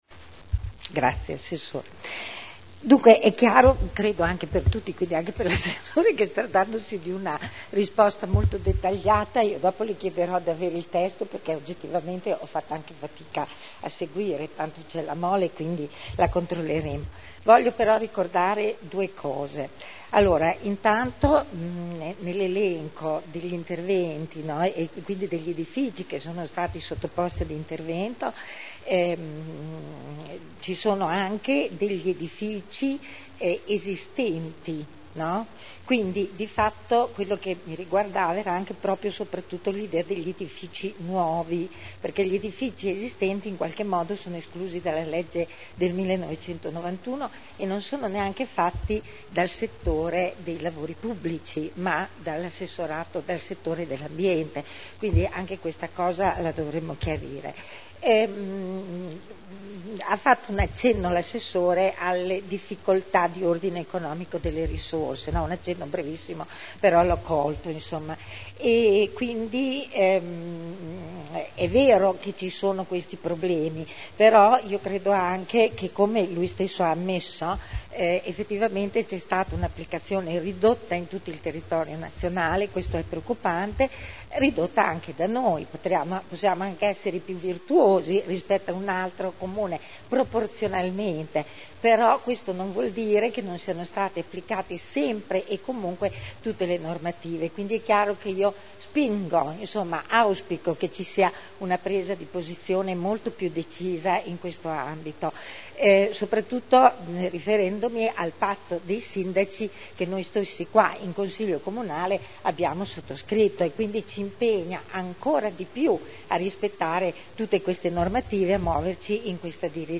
Interrogazione della consigliera Rossi E. (IdV) sul risparmio energetico